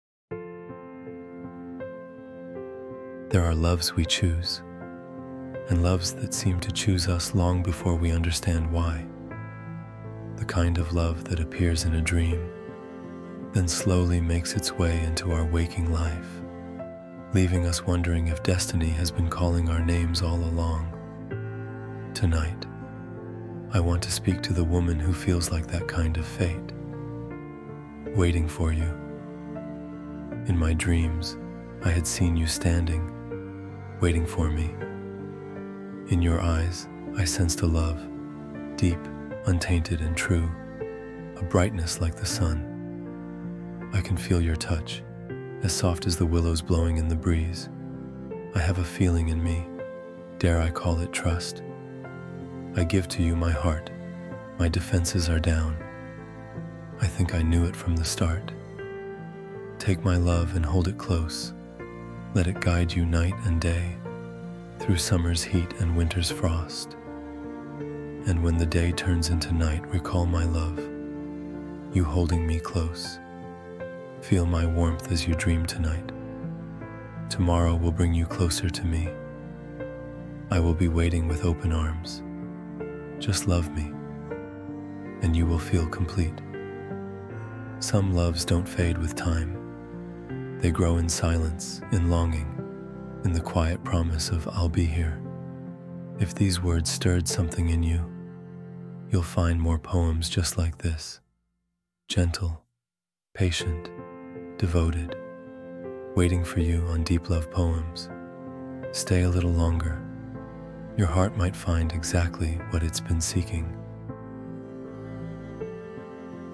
“Waiting for You” is a soft, emotional love poem spoken from a man’s heart to the woman he longs for.